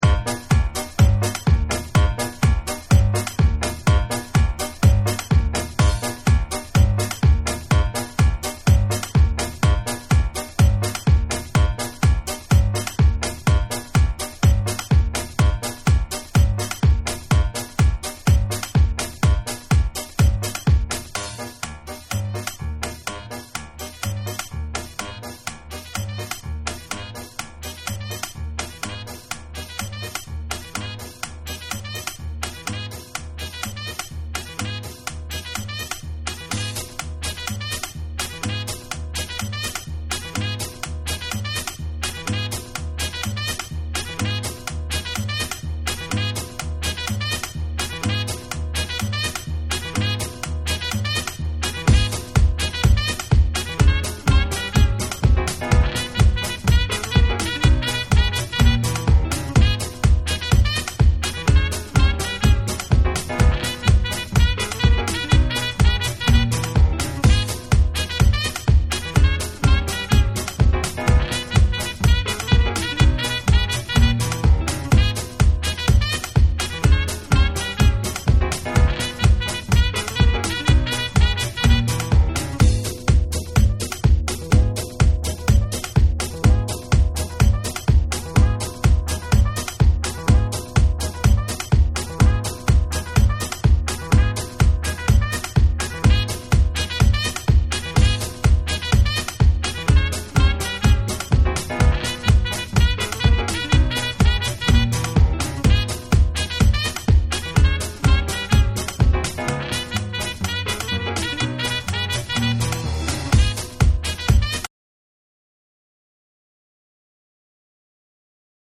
TECHNO & HOUSE / BREAKBEATS